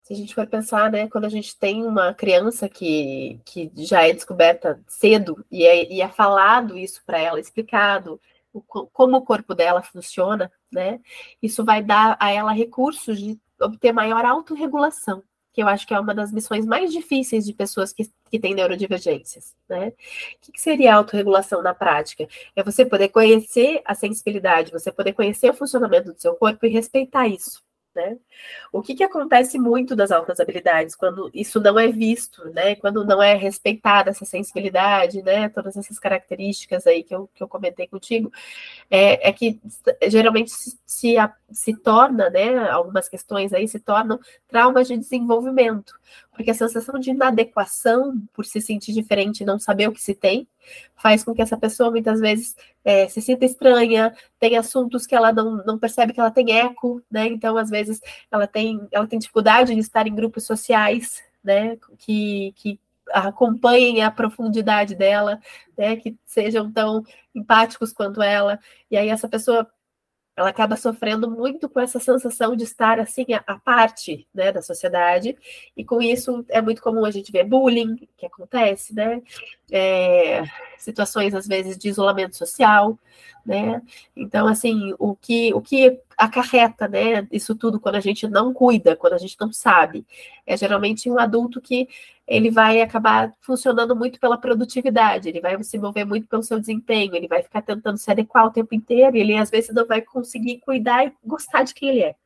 Fala da psicóloga
Entrevista